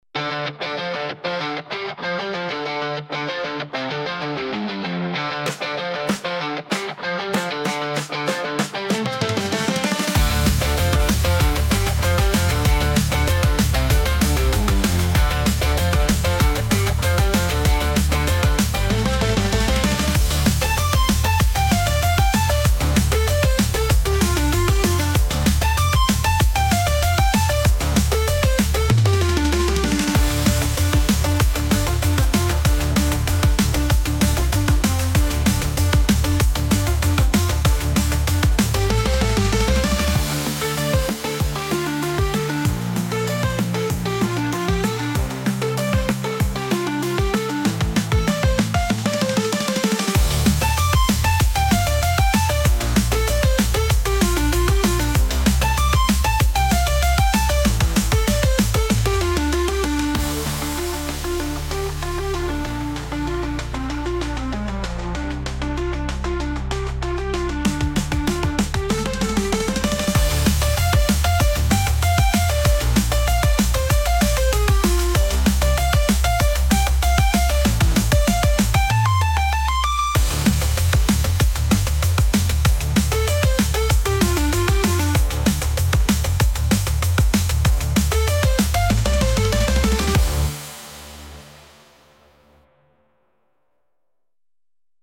ゲームの戦闘音楽みたいなロック